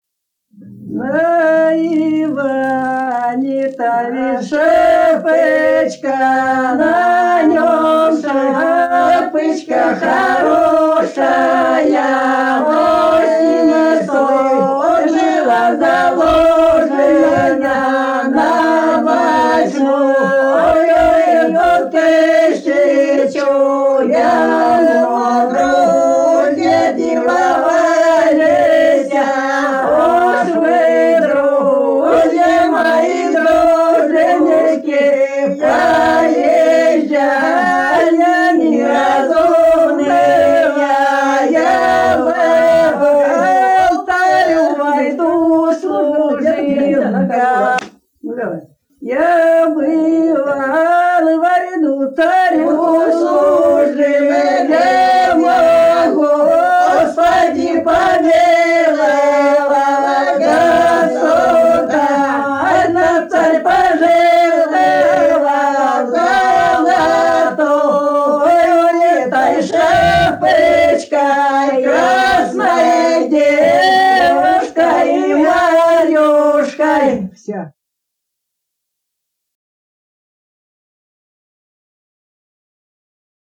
Народные песни Касимовского района Рязанской области «Па Иване-то есть шапочка», свадебная.